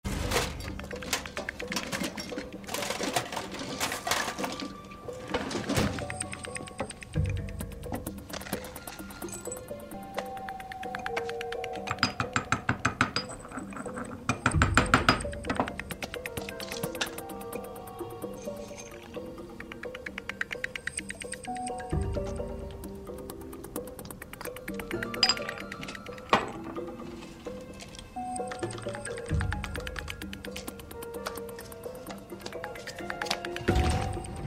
Réponse : House écrase des amphétamines avec un pilon et met la poudre dans un café qu'il offrira à Wilson (3x22 Resignation)